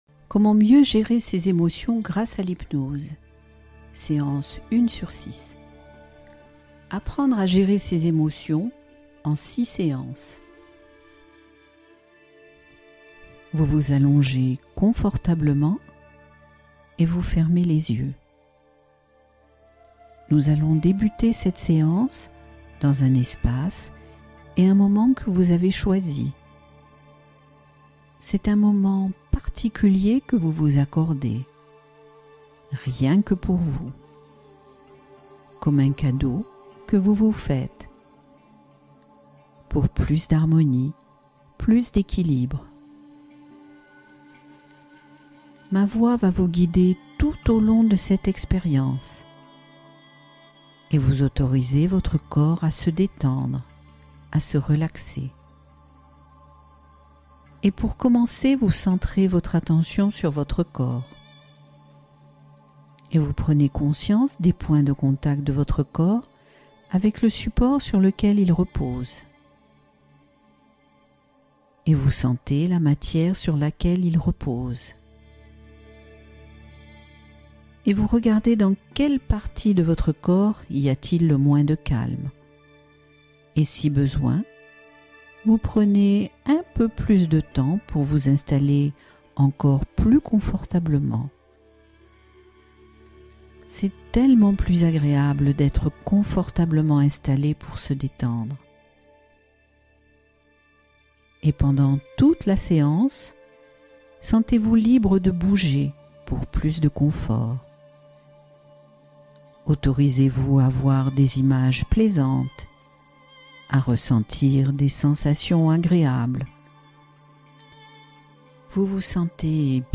Gérer ses émotions : séance guidée d’apaisement